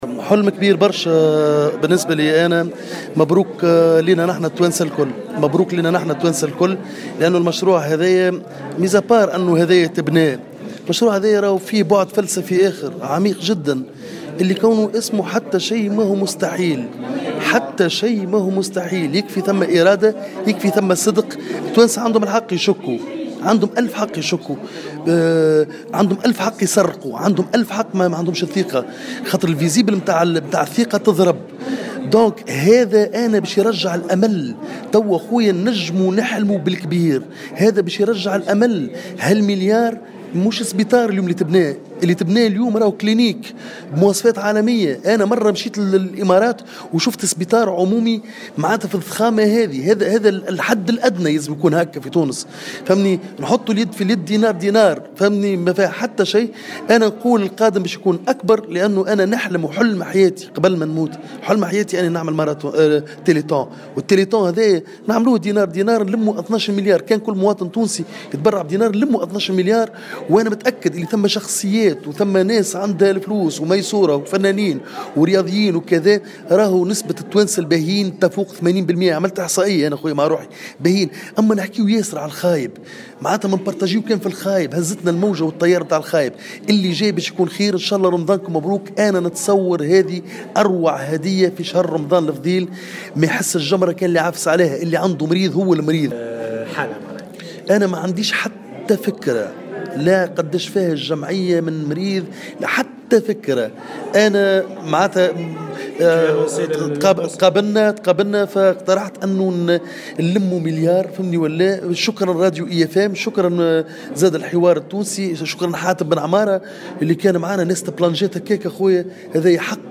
قال الممثل الكوميدي جعفر القاسمي في تصريح لمراسل الجوهرة "اف ام" اليوم الإثنين 14 ماي 2018 أن حلم جمع مليار لفائدة جمعية مرام تحقق ليؤكد أن المستحيل ليس تونسيا ويكفي أن يكون هنا صدق وإرادة لتحقيق أكثر من ذلك.